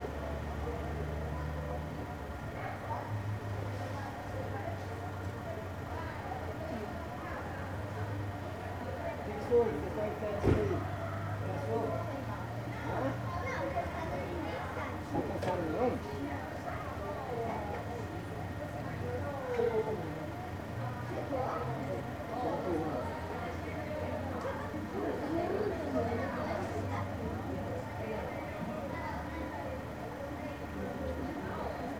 CSC-04-255-LE - Ambiencia saida de escola no estacionamento pessoas criancas e carro parado ligado.wav